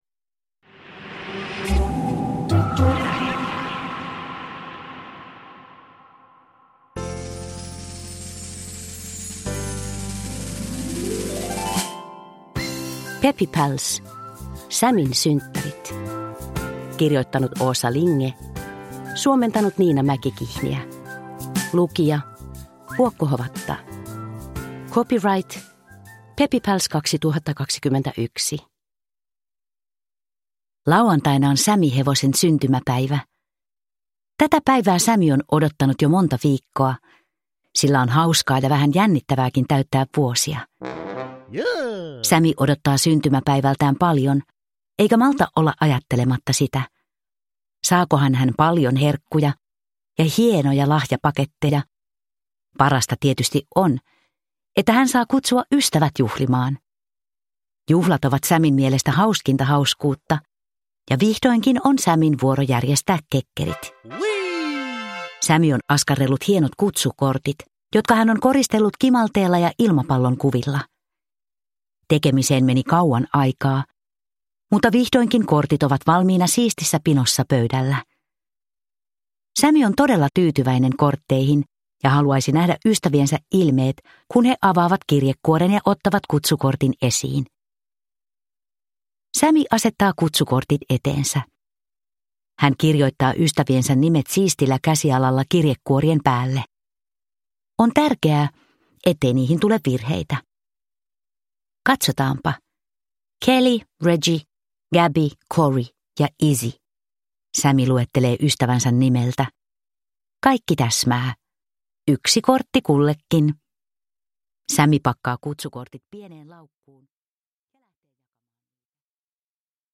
Peppy Pals: Sammyn synttärit – Ljudbok – Laddas ner